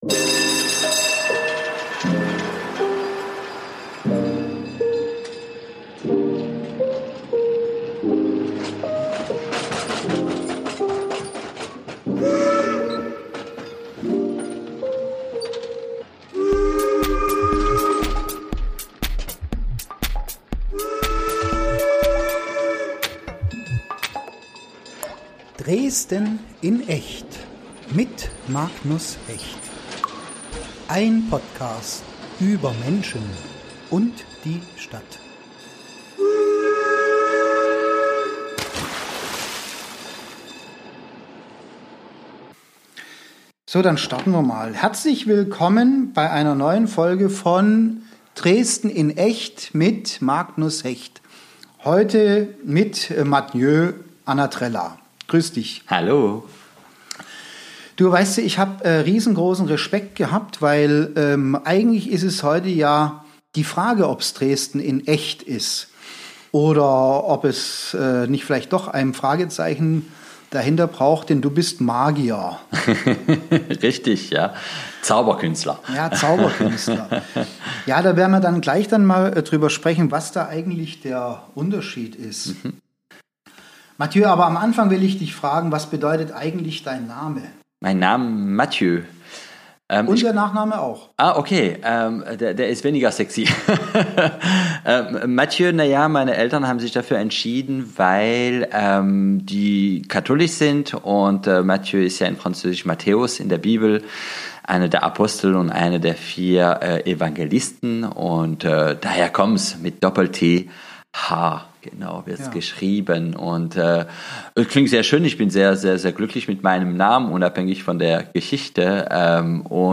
Inklusive audio-Zaubertrick